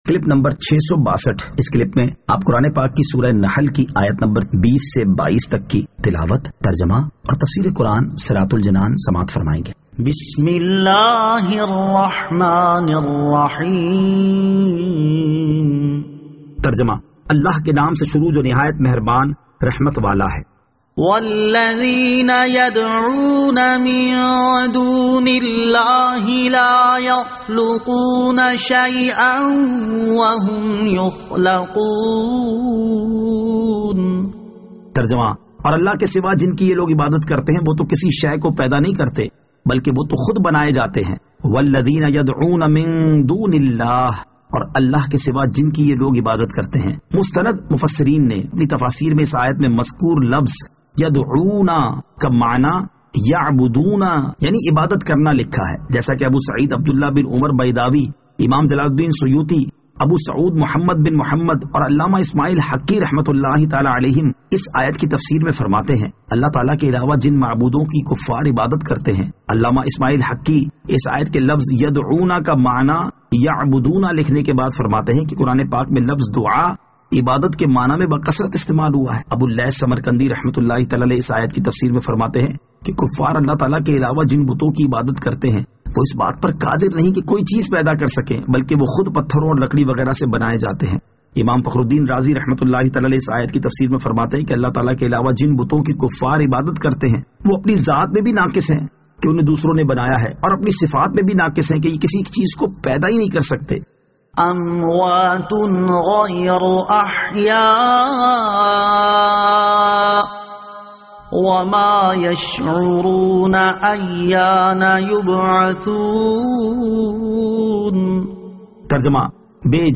Surah An-Nahl Ayat 20 To 22 Tilawat , Tarjama , Tafseer